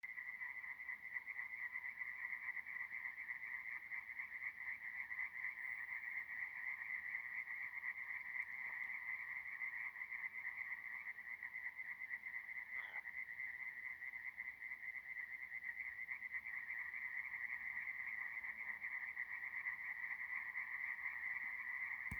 Kokvarde, Hyla arborea
Skaits25
StatussDzied ligzdošanai piemērotā biotopā (D)
Piezīmes/svaigā izcirtumā, appludušajās harvestera risēs.